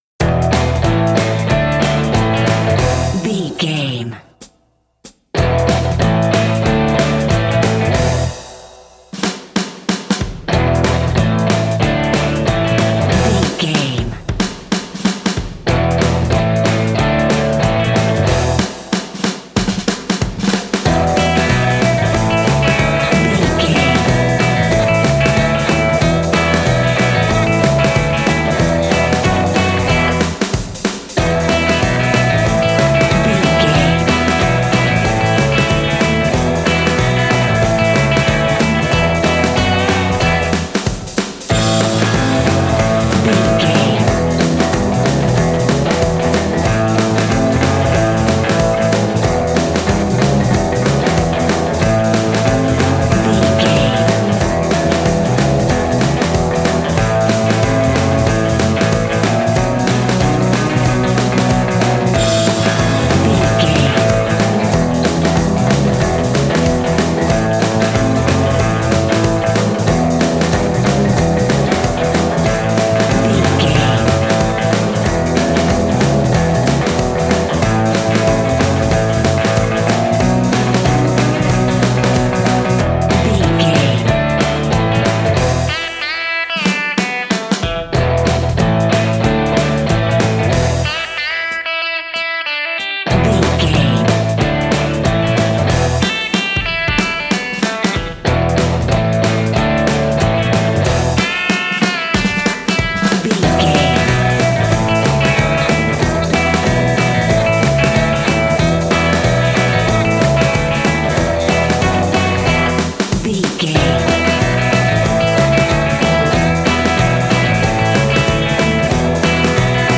Ionian/Major
uplifting
bass guitar
electric guitar
drums
cheerful/happy